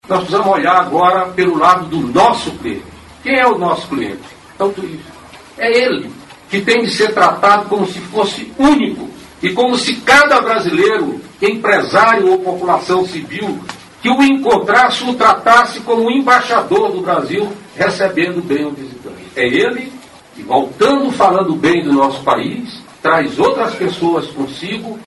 aqui e ouça fala na qual o ministro do Turismo, Gastão Vieira, frisa que um bom atendimento pode reforçar a atração de visitantes ao Brasil.